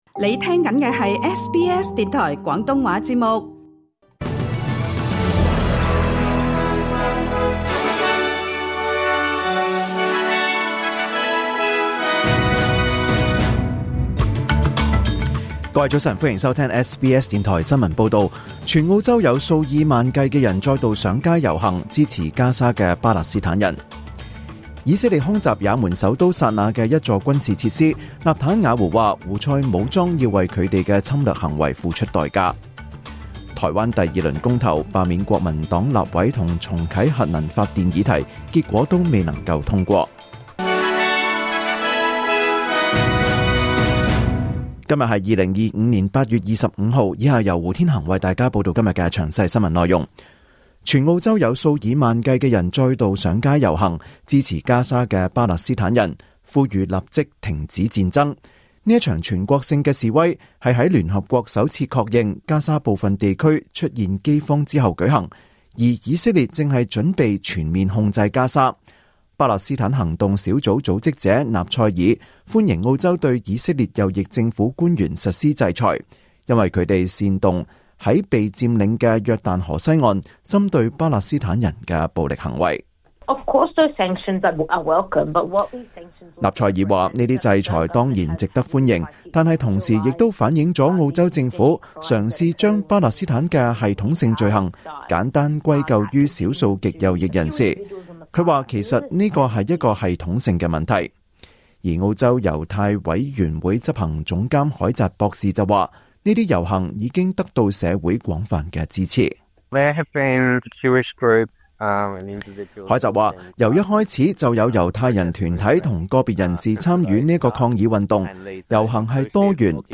2025年8月25日 SBS 廣東話節目九點半新聞報道。